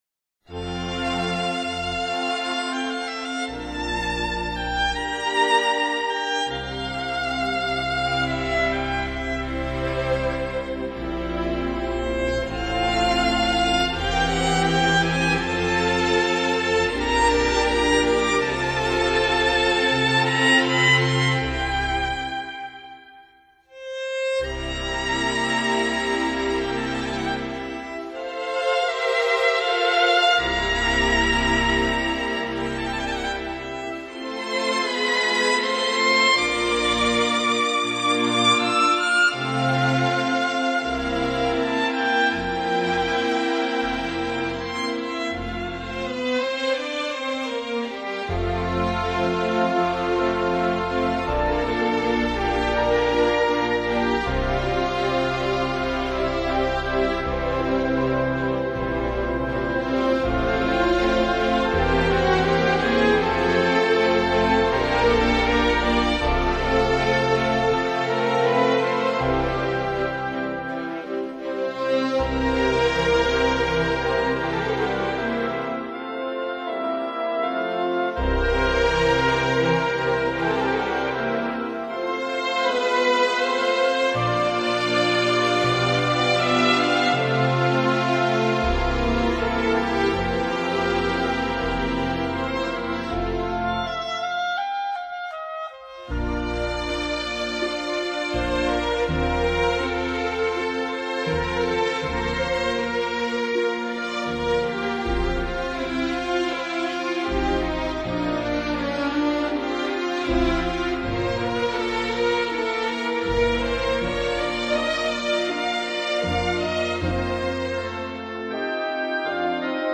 Genre:Classical
Style:Modern Classical